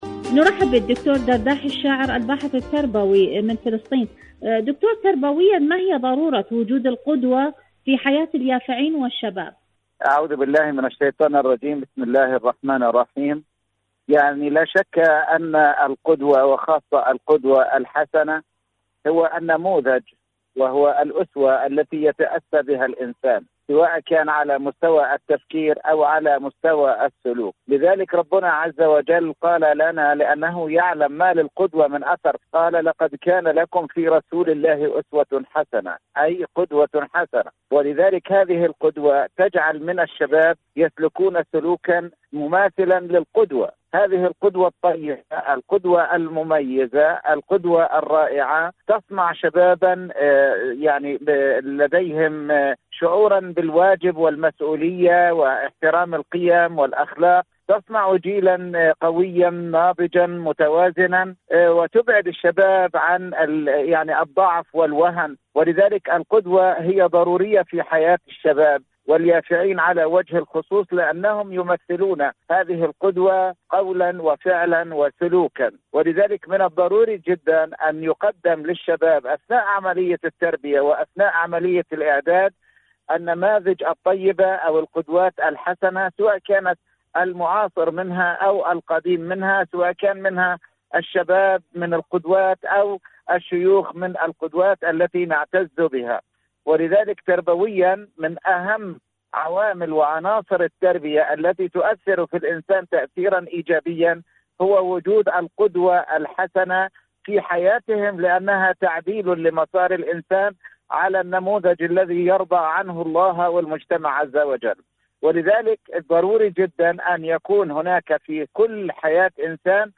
إذاعة طهران-دنيا الشباب: مقابلة إذاعية